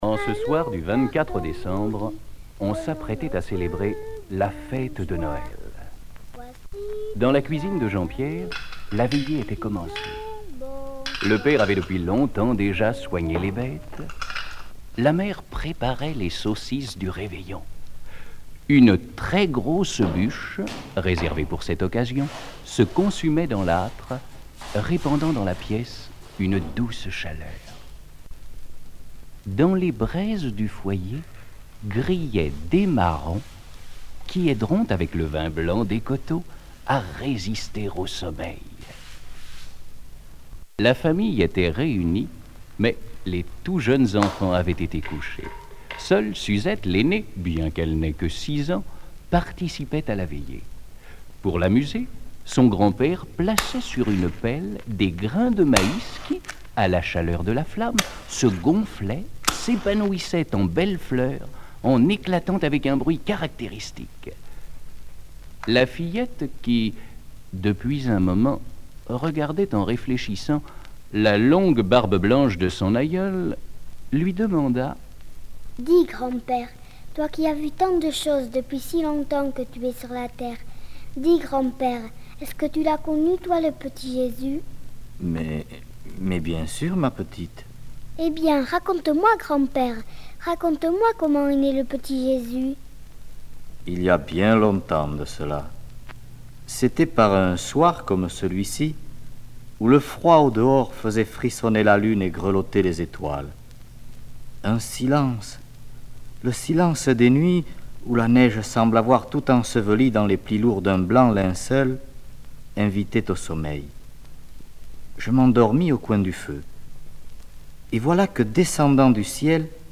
Genre conte